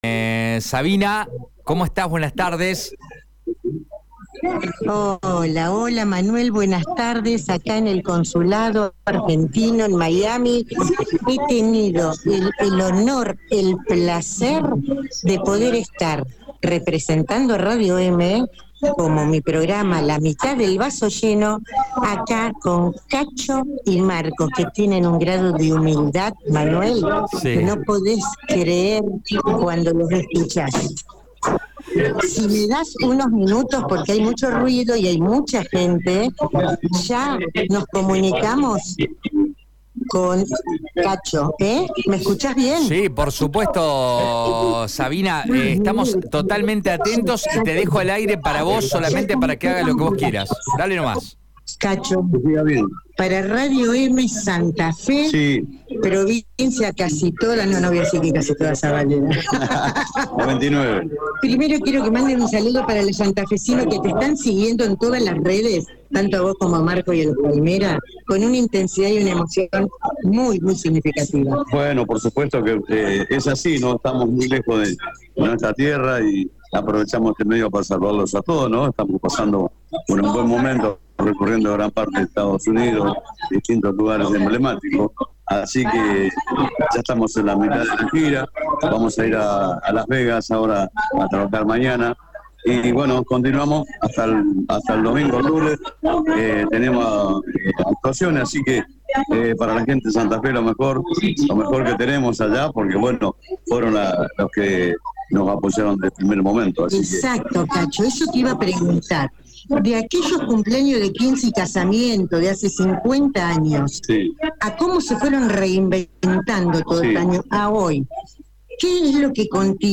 estuvo presente representando a la radio más grande de la provincia en la conferencia de prensa que el grupo tropical «Los Palmeras» dio en la ciudad de Miami